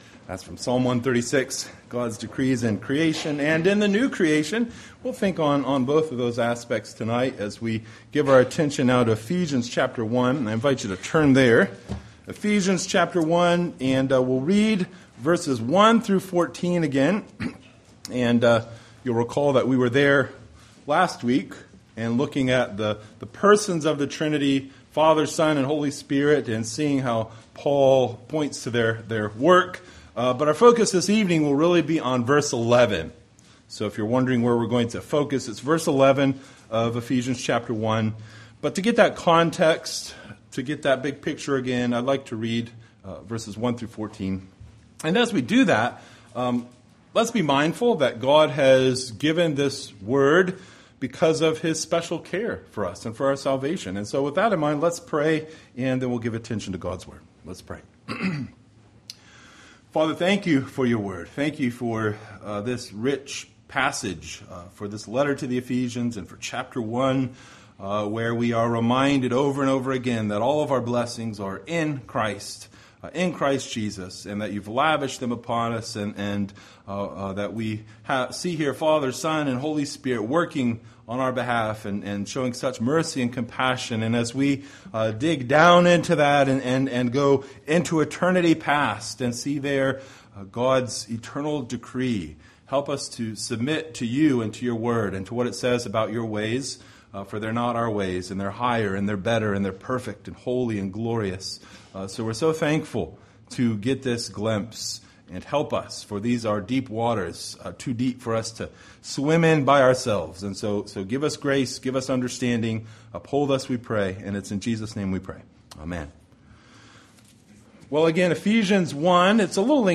Ephesians 1:1-14 Service Type: Sunday Evening Related « Falling Away Falling Away